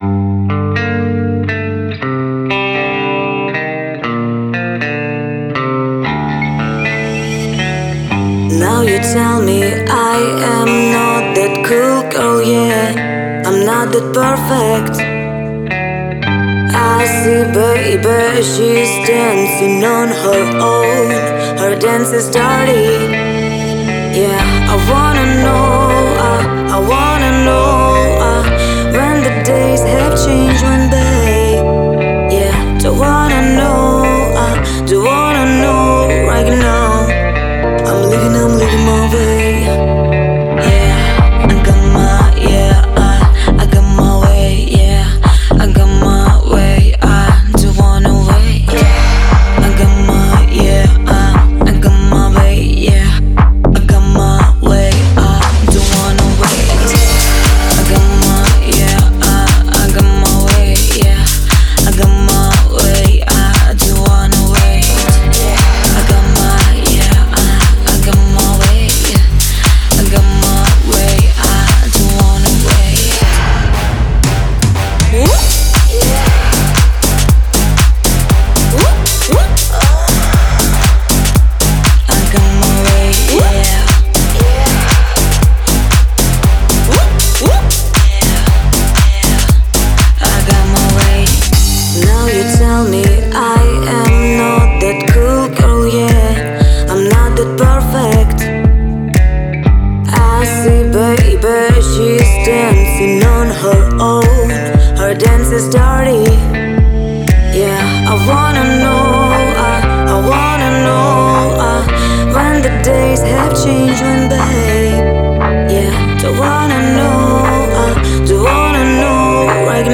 это энергичная композиция в жанре EDM
а динамичные биты заставляют двигаться и подпевать.